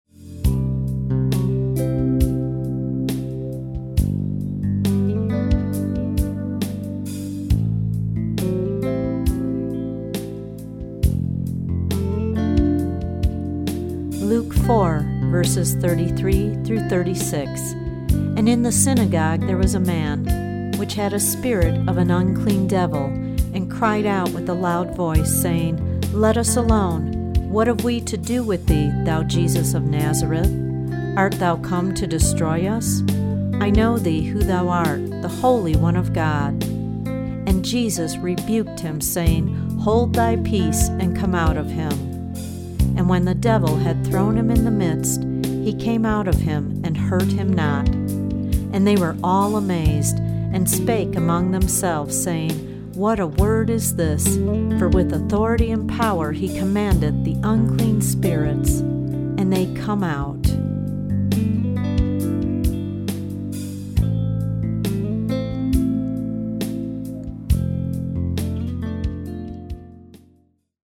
original anointed instrumental music on six CD’s.  The healing CD contains every instance of Jesus’ healings in chronological order.
anointed instrumental music is a soothing backdrop